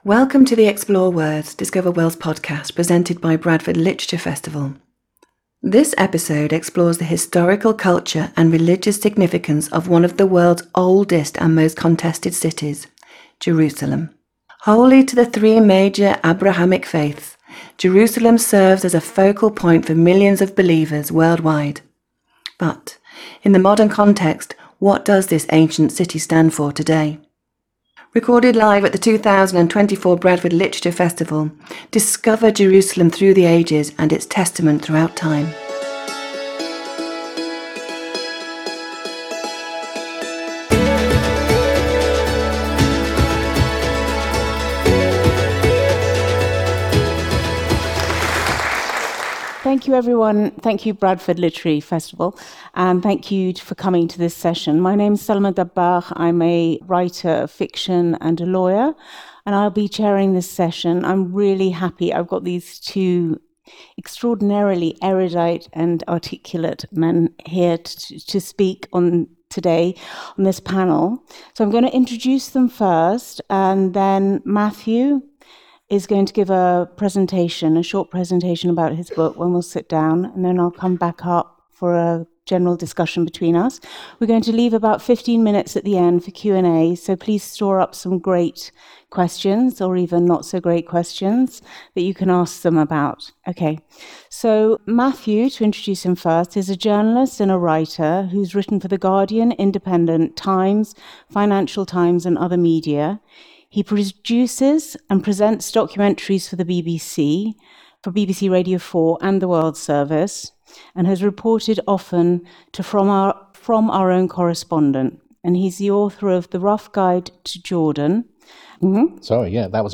Join this fascinating conversation, we explore the historical, cultural and religious significance of one of the world’s oldest and most contested cities, which is holy to the three major Abrahamic faiths.